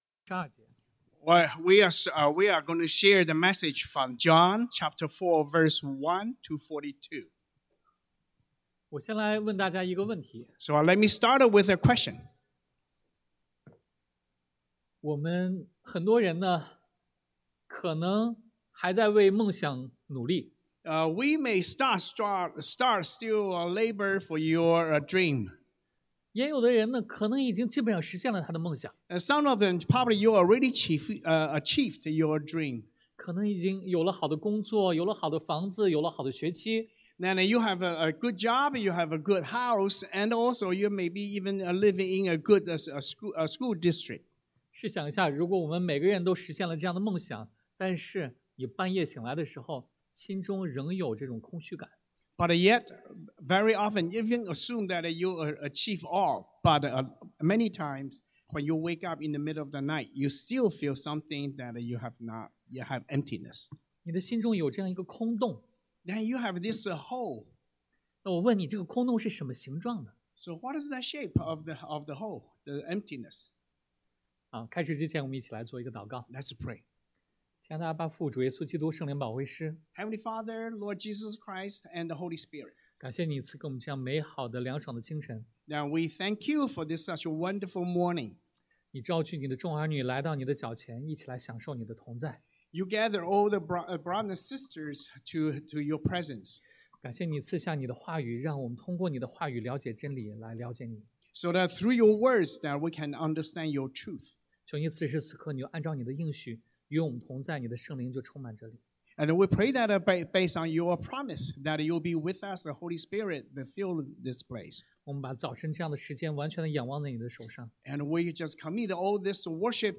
Passage: 约翰福音 John 4:1-42 Service Type: Sunday AM